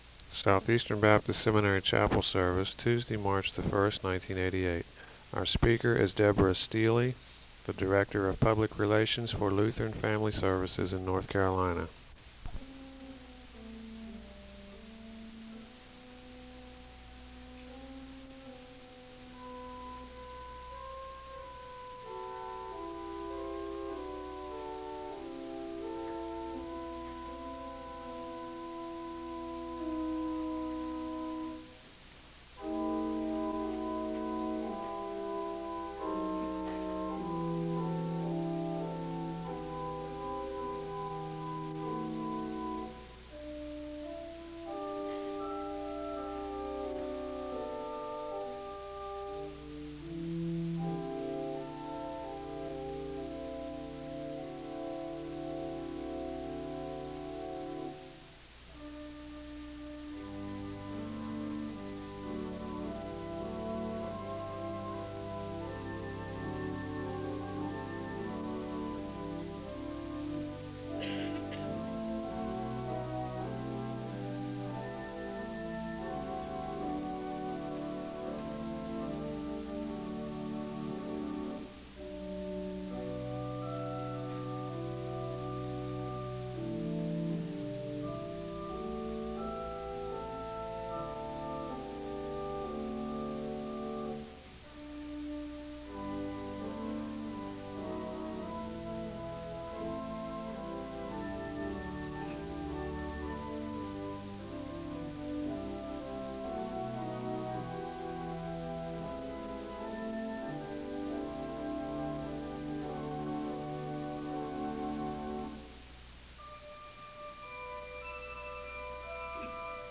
The service begins with organ music (0:00-3:50). There is a Scripture reading (3:51-4:16).
There are Scripture readings from Amos and Matthew (6:38-9:45). There is a moment of silence and a word of prayer (9:46-11:36).